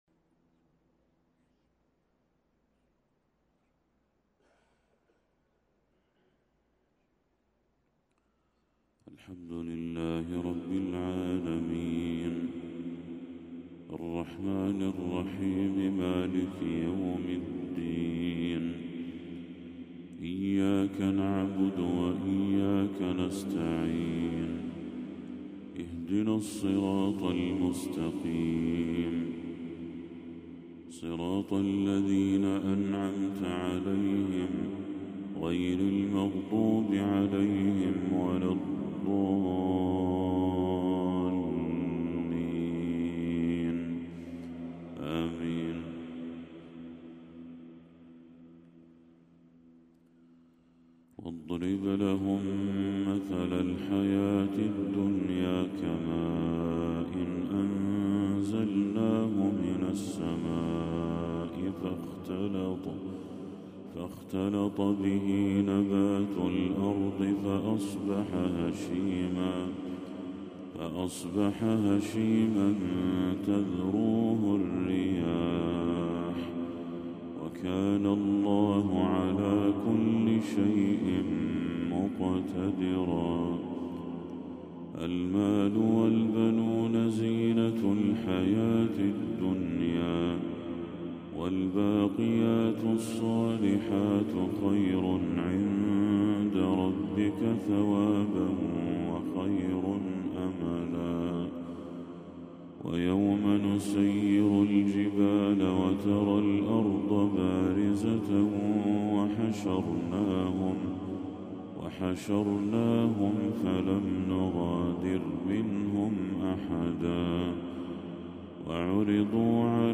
تلاوة خاشعة من سورة الكهف